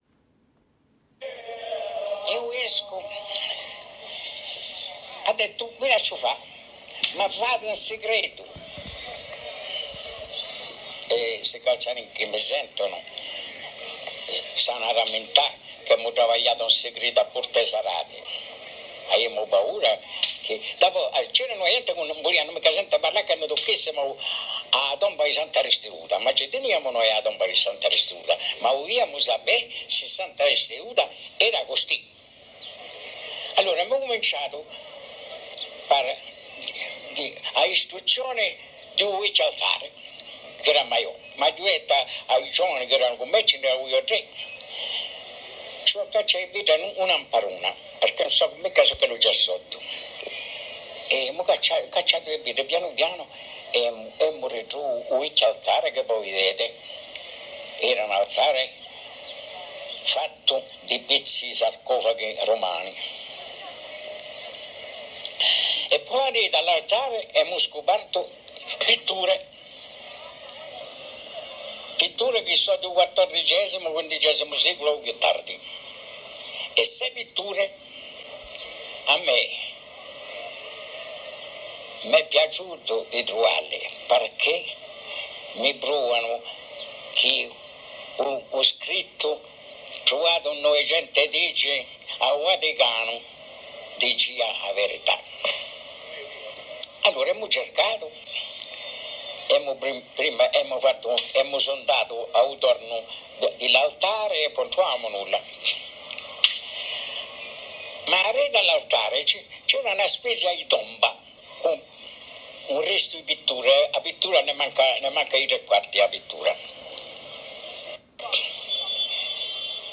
Sa voix, rocailleuse, ferme et pressée, nous livre un récit en langue corse d’une beauté particulièrement émouvante.
voix-chanoine-copie.mp3